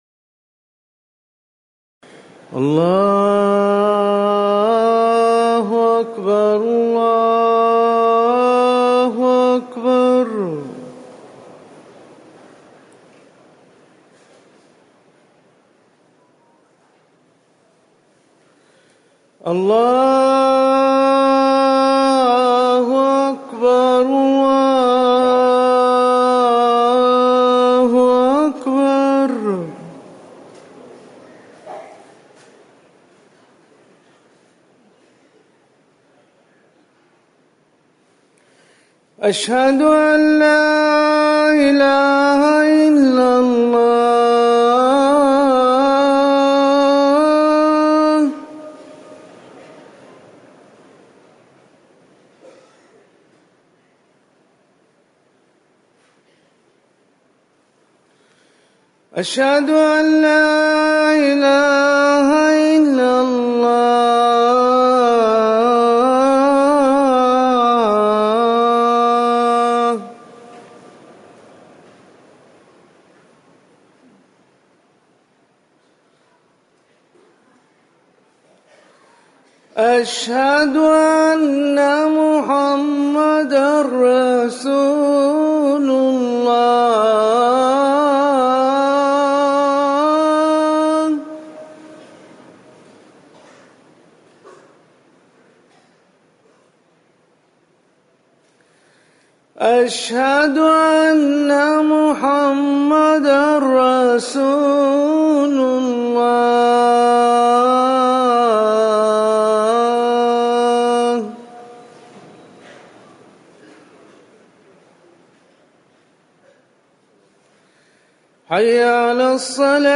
أذان العشاء
تاريخ النشر ٣٠ محرم ١٤٤١ هـ المكان: المسجد النبوي الشيخ